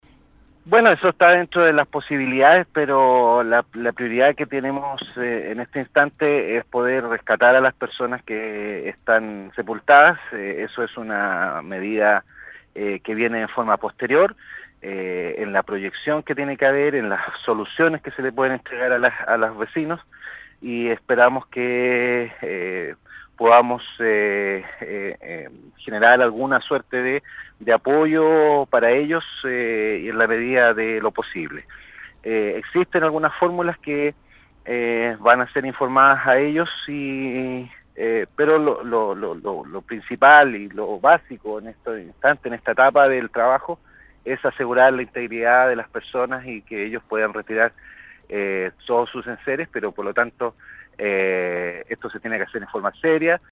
El Gobernador de Palena Carlos Salas dijo que antes de pensar en una reconstrucción o relocalización de Villa Santa Lucía, se trabajará hasta ubicar a la última de las 8 personas que permanecen desaparecidas en la zona, tras el alud del sábado La autoridad provincial, en conversación con el programa «Primera Hora» de Radio Sago de Puerto Montt, reconoció que los pronósticos meteorológicos apuntan a un escenario riesgoso en la zona, con peligro real de nuevas remociones en masa.